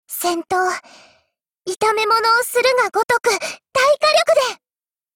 贡献 ） 协议：Copyright，人物： 碧蓝航线:龙武语音 ，其他分类： 分类:碧蓝航线:龙武语音 您不可以覆盖此文件。
Cv-50105_warcry.mp3